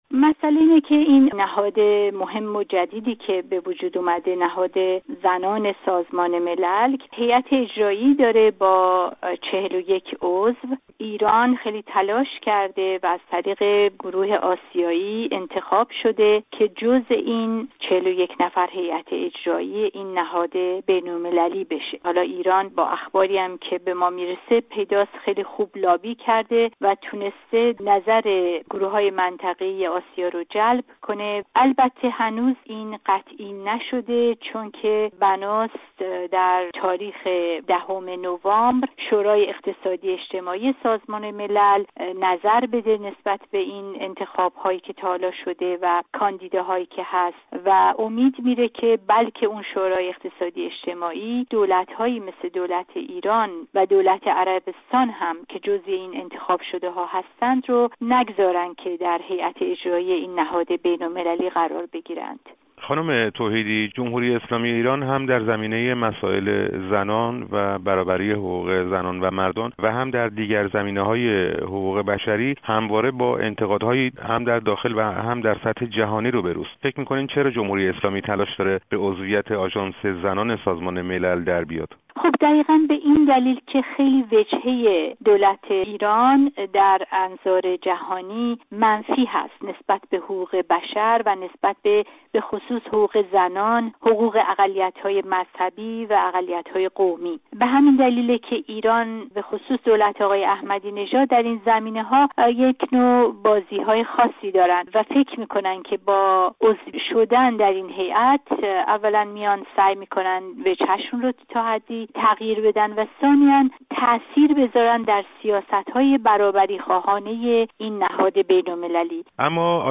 گفت‌و گوی